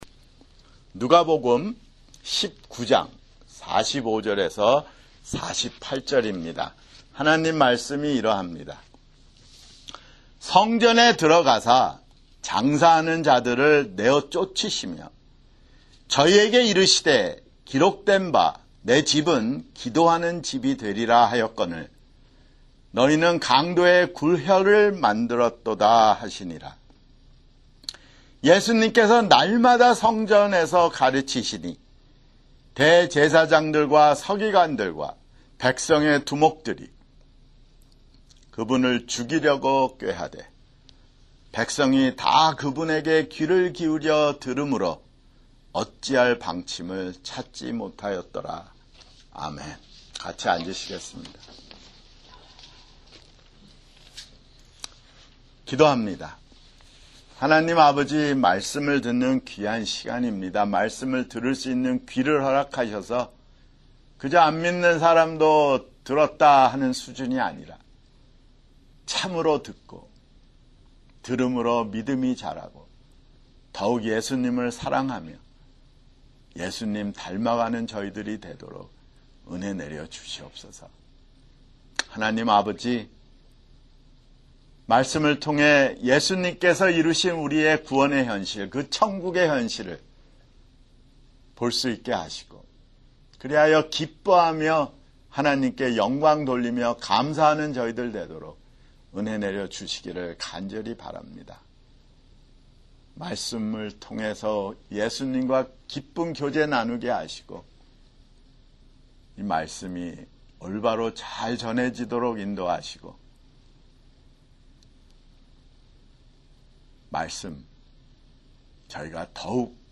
[주일설교] 누가복음 (129)